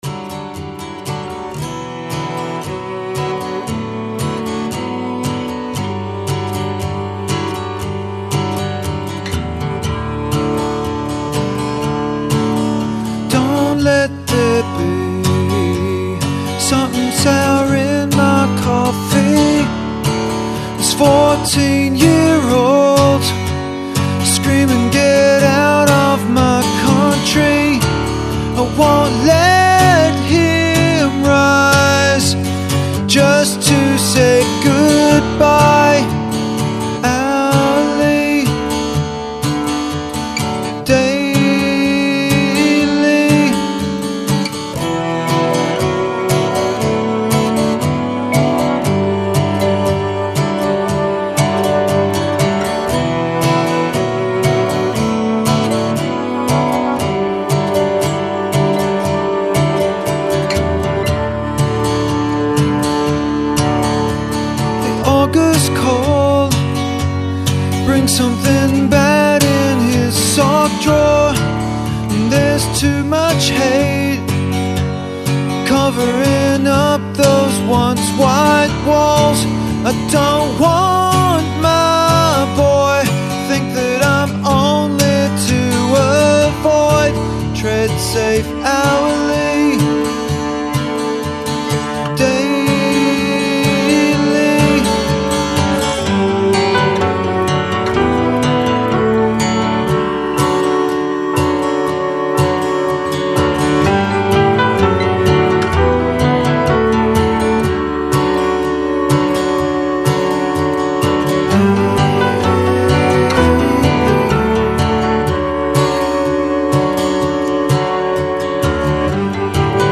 indiepolice 8-pop folk session.mp3